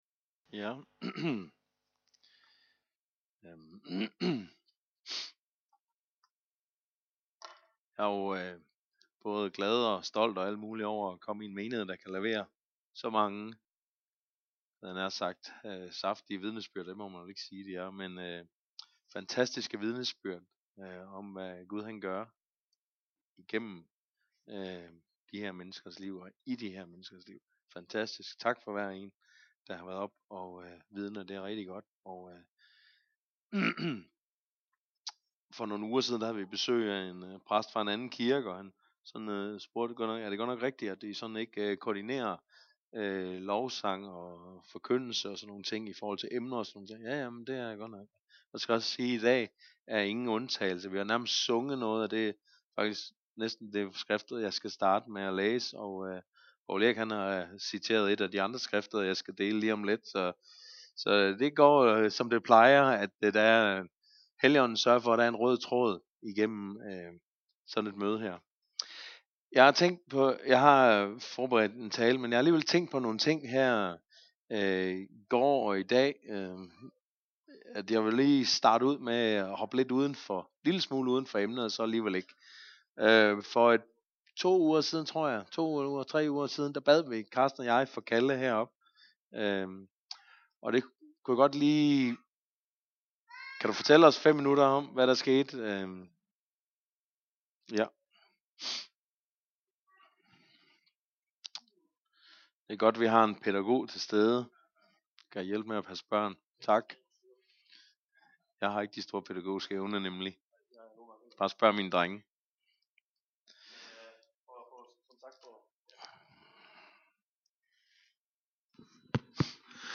at modtage åbenbaring) - Gudstjeneste